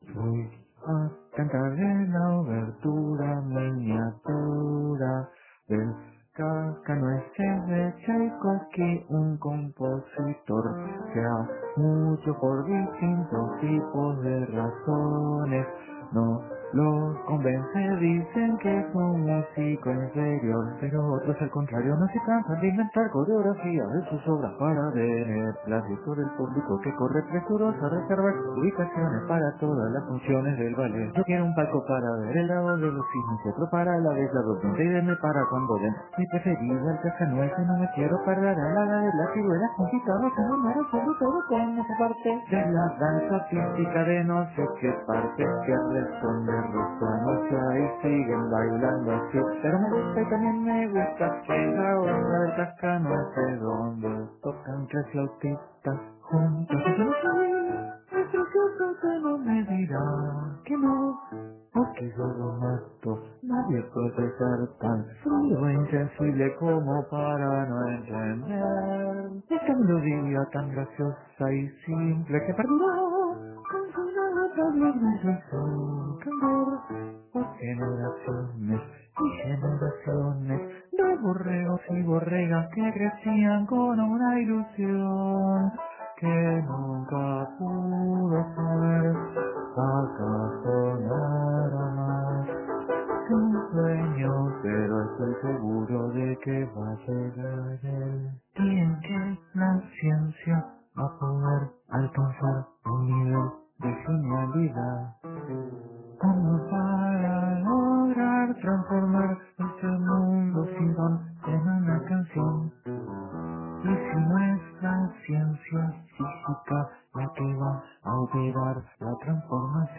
Entrevista con Leo Masliah.